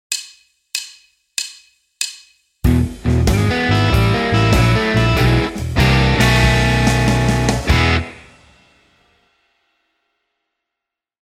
В стандартном 12-тактовом блюзе обороты обычно используются в последних двух тактах.
В первом такте используются нисходящие звуки в рамках аккорда Ми. Аккорд B9 во втором такте - это блюзовая вариация аккорда B7, который и перезапускает наш оборот.